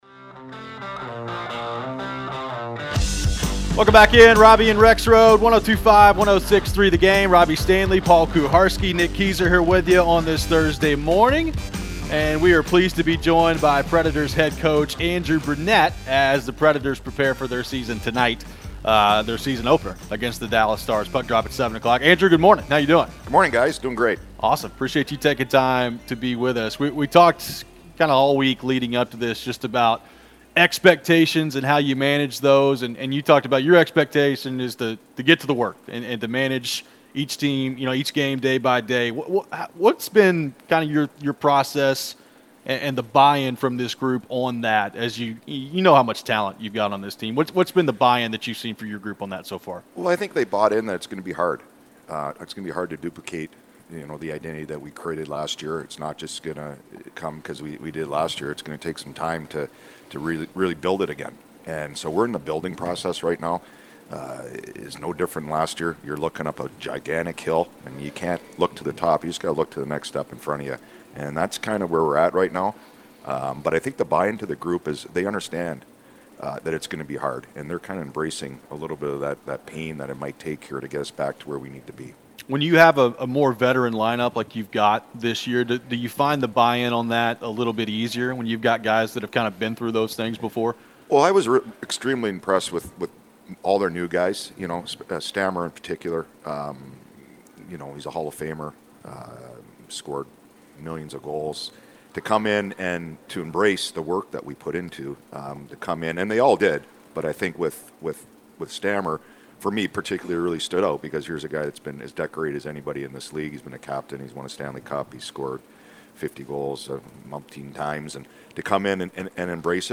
Andrew Brunette Interview (10-10-24)